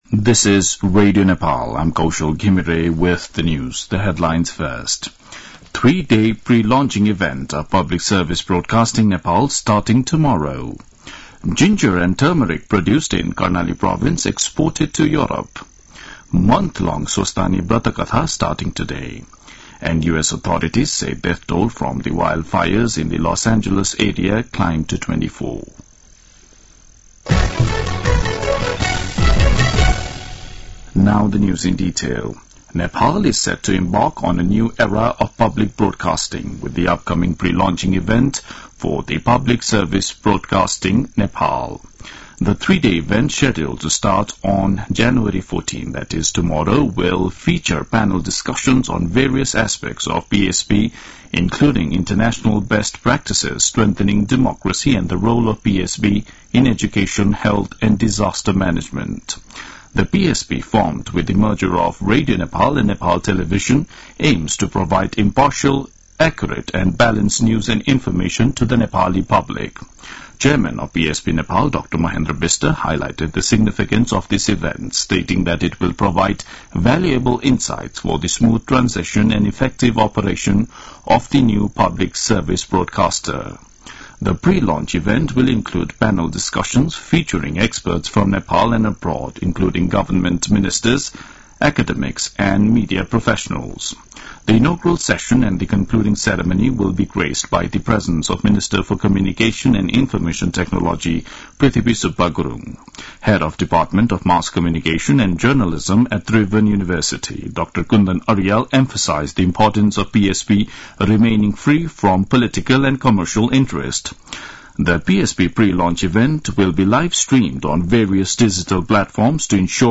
दिउँसो २ बजेको अङ्ग्रेजी समाचार : १ माघ , २०८१